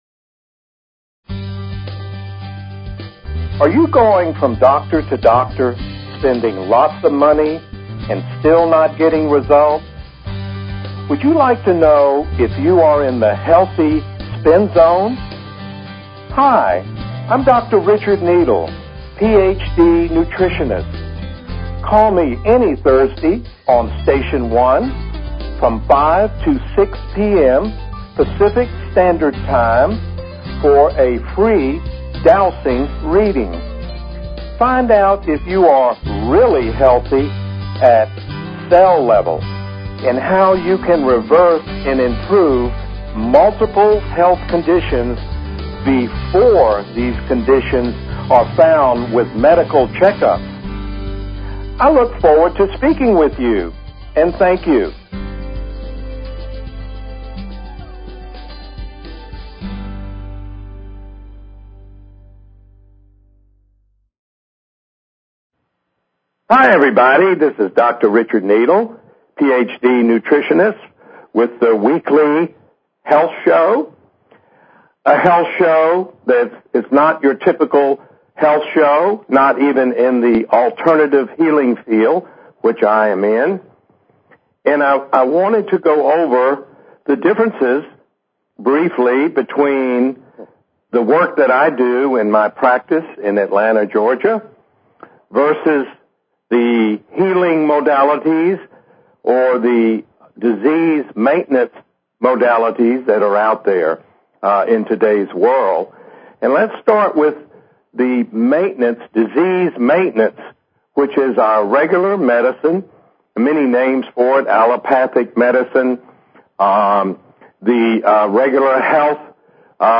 Talk Show Episode, Audio Podcast, Dowsing_for_Health and Courtesy of BBS Radio on , show guests , about , categorized as